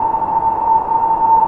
SONAR-PAD.wav